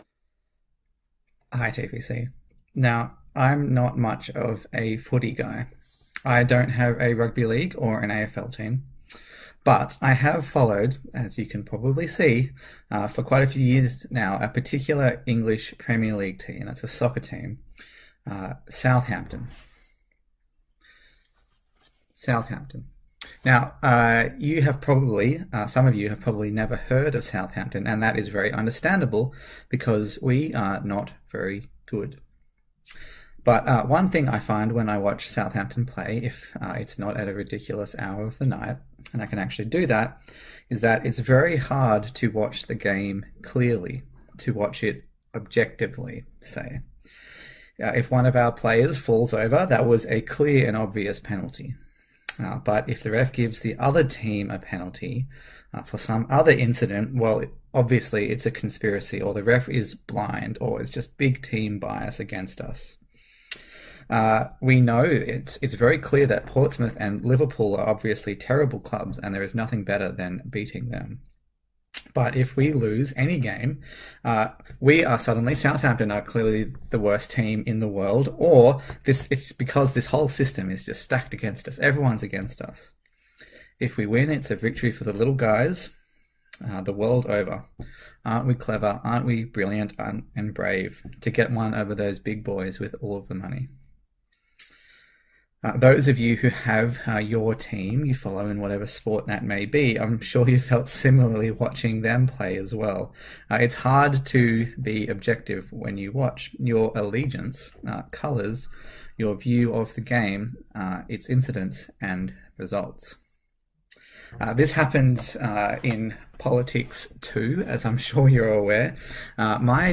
Service Type: Sunday Morning A sermon in the series on the book of Philippians